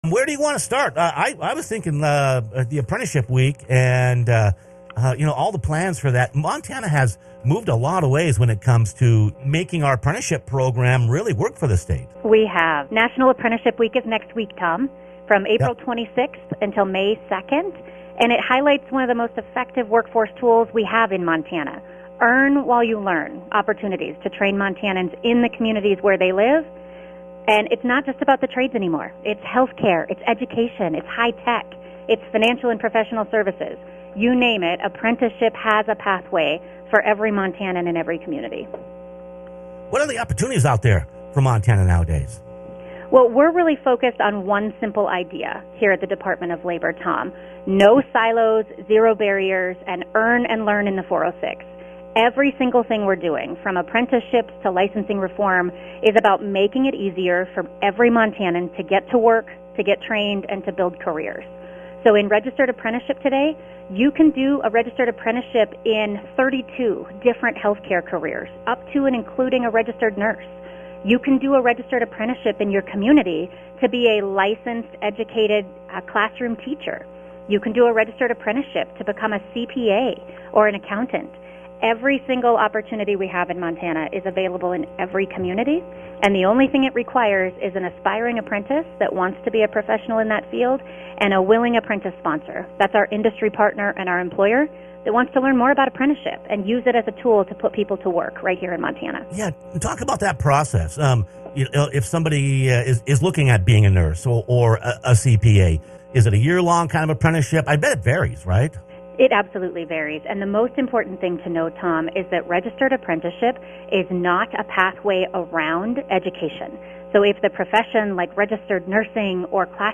Montana has upped the game when it comes to apprenticeships. Click on the podcast as Department of Labor and Industry Commissioner Swanson Swanson discusses the state's approach to helping businesses find the labor they need, and leading employees to more choices and opportunities. 406Jobs, AI, and much more on the plate.